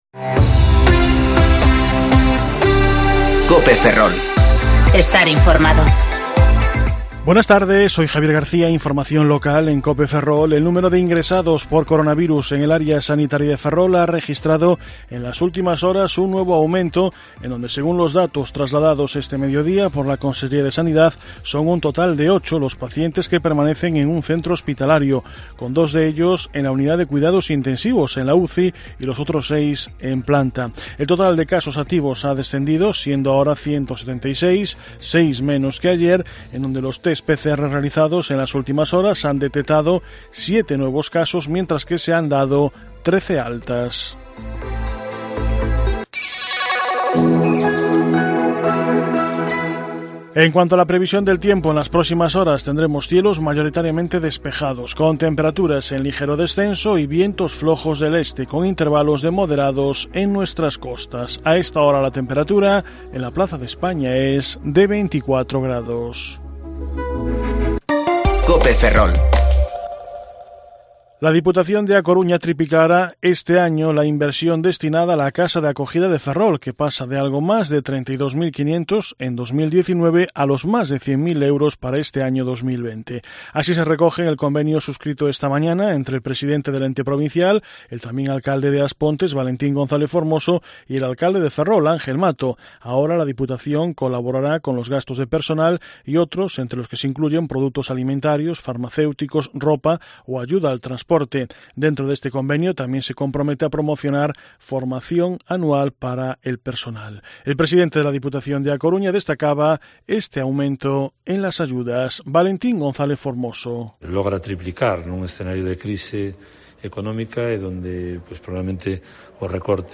Informativo Mediodía COPE Ferrol 18/9/2020 (De 14,20 a 14,30 horas)